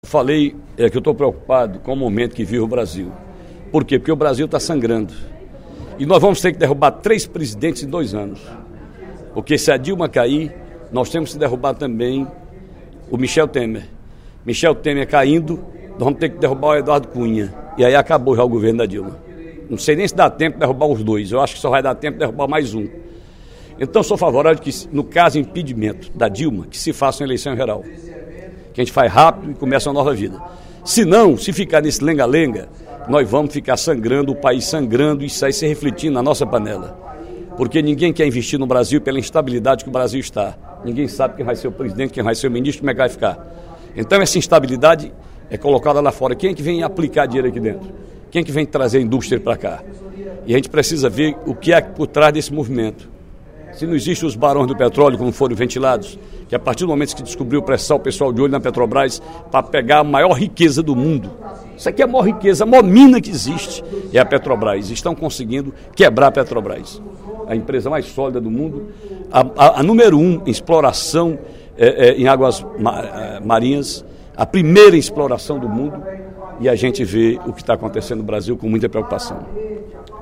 O deputado Ferreira Aragão (PDT) disse, durante o primeiro expediente da sessão plenária desta sexta-feira (08/04), ser a favor do impeachment da presidente Dilma Rousseff “apenas em caso de haver eleições gerais”.